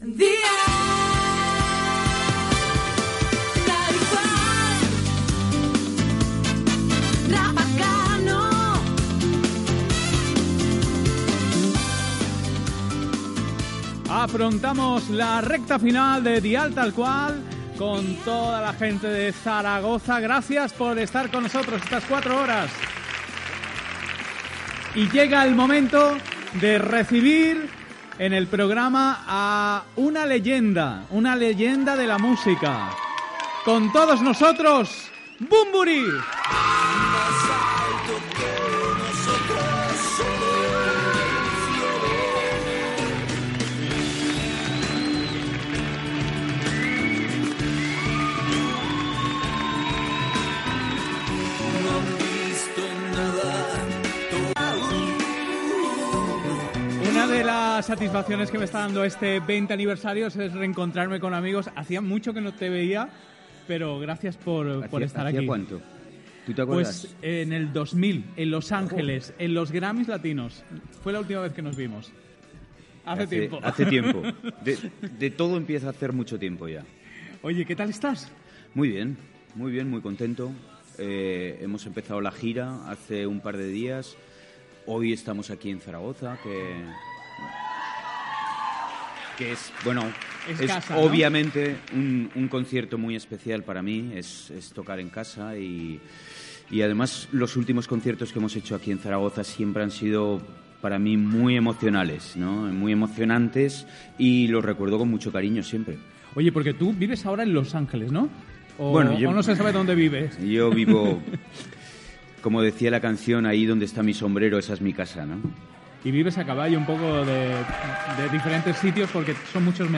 Especial des de Saragossa. Indicatiu de la ràdio, presentació i entrevista al cantant Enrique Bunbury.
Musical